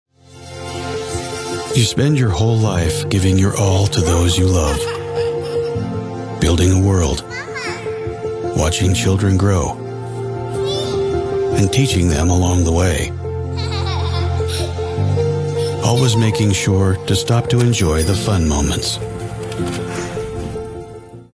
• Authentic and relatable — a real human presence, not a polished announcer veneer
• Gritty and grounded — forged in the mountains, with a modern Western edge
• Warm and trustworthy — the voice of a guide, not a salesman
Commercial Demo